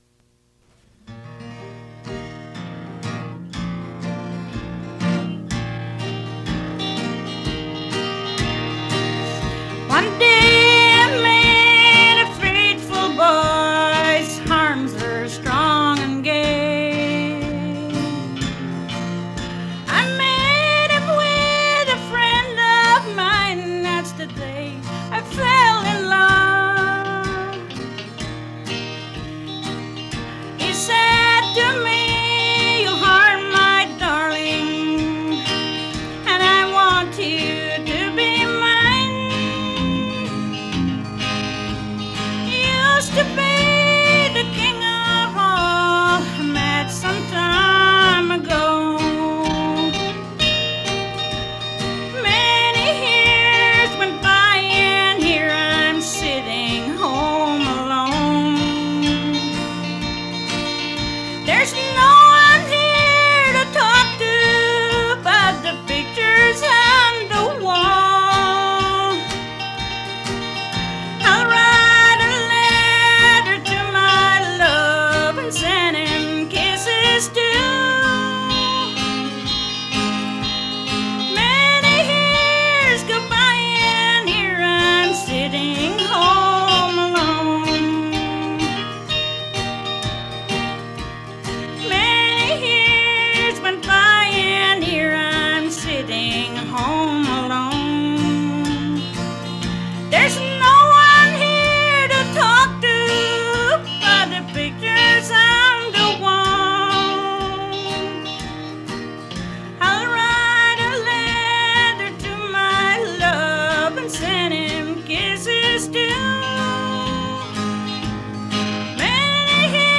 Fait partie de Country music recording in the studio